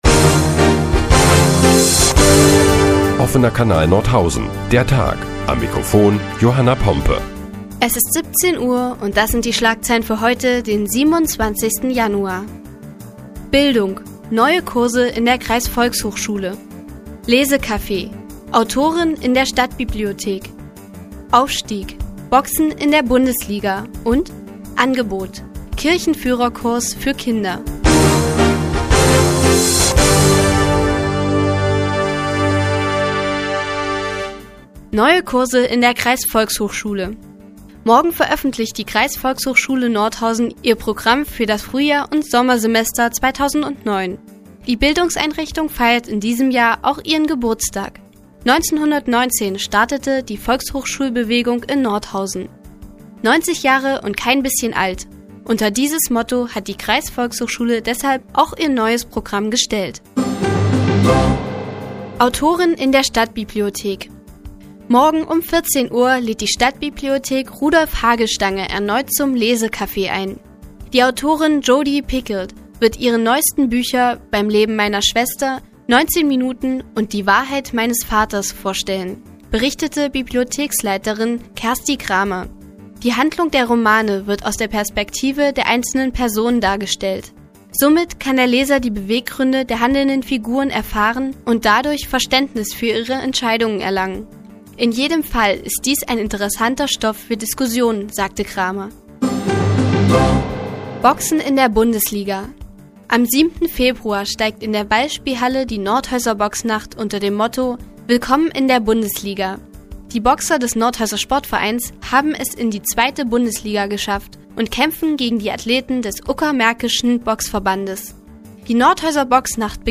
Die tägliche Nachrichtensendung des OKN ist nun auch in der nnz zu hören. Heute geht es unter anderem um "Lesecafé" in der Stadtbibliothek und einen Kirchenführerschein für Kinder.